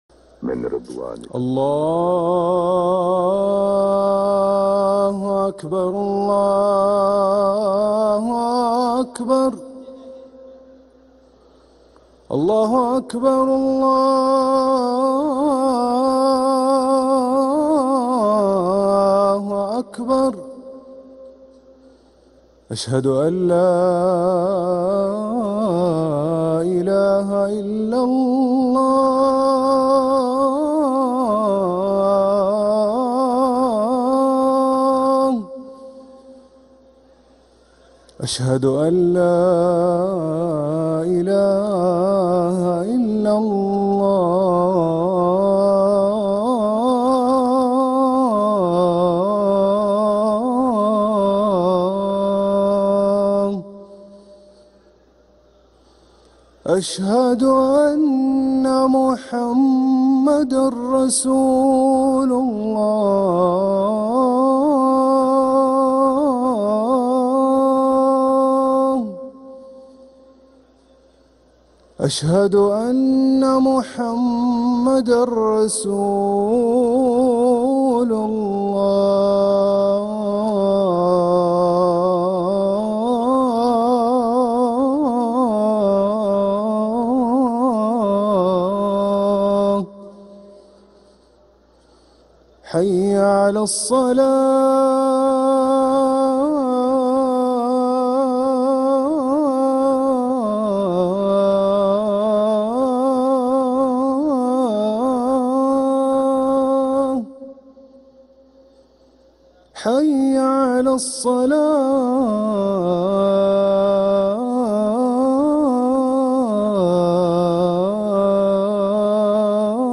أذان العشاء للمؤذن هاشم السقاف الأربعاء 1 ربيع الأول 1446هـ > ١٤٤٦ 🕋 > ركن الأذان 🕋 > المزيد - تلاوات الحرمين